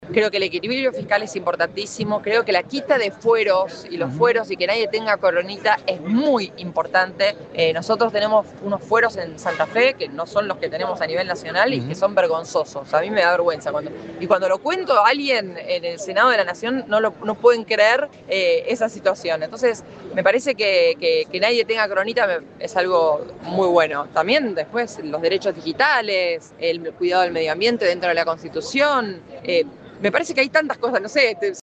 La senadora nacional por Santa Fe, Carolina Losada, participó como invitada especial en el acto de apertura de la Convención Constituyente que dará inicio a la reforma de la Carta Magna provincial, un proceso histórico que busca actualizar la Constitución de 1962 tras 63 años sin modificaciones.
Durante el evento, realizado en la Legislatura de Santa Fe, Losada destacó la importancia de la reforma y puso énfasis en uno de los temas clave del debate: la eliminación de los fueros parlamentarios.
SENADORA-LOSADA-SOBRE-REFORMA.mp3